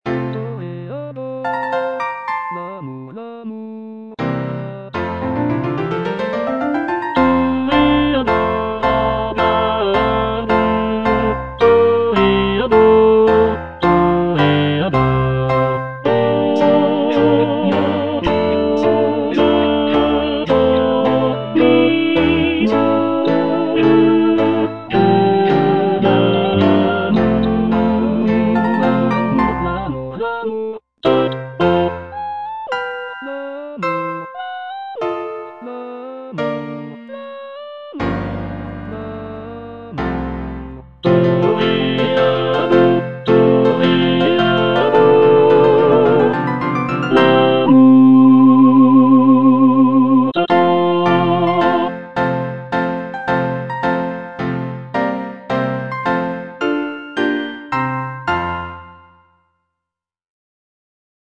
G. BIZET - CHOIRS FROM "CARMEN" Toreador song (II) (tenor I) (Emphasised voice and other voices) Ads stop: auto-stop